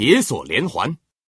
FreeKill / packages / maneuvering / audio / card / male / iron_chain.mp3